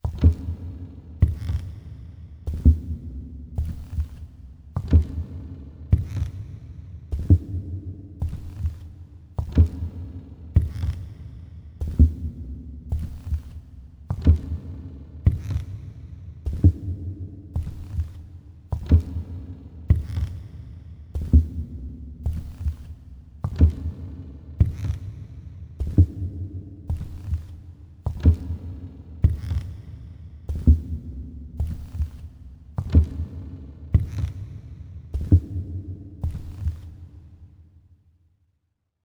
FootstepWood DSS02_17_1.wav